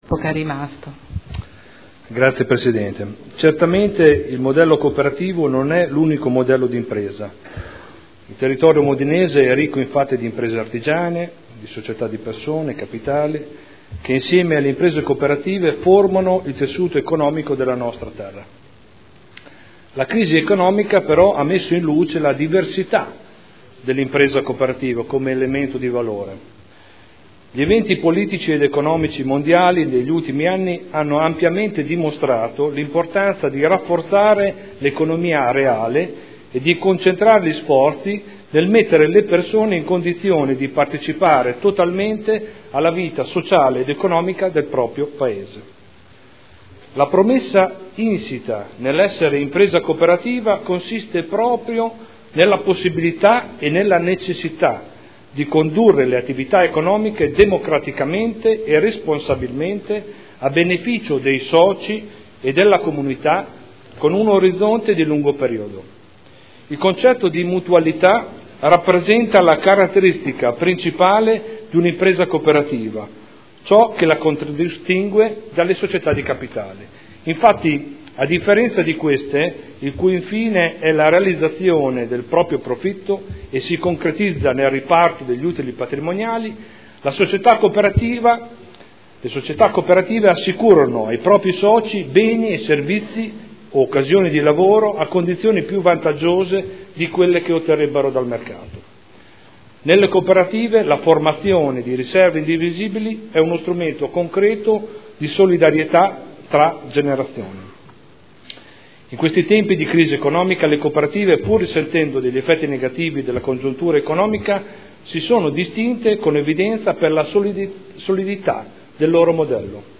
Stefano Prampolini — Sito Audio Consiglio Comunale
Dibattito su celebrazione dell’Anno internazionale delle cooperative indetto dall’ONU per il 2012